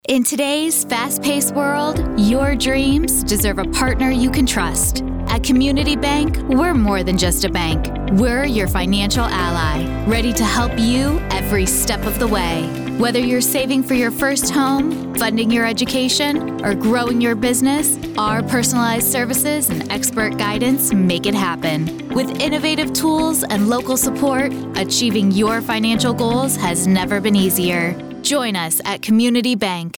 ad samples.
Bank-Commercial.mp3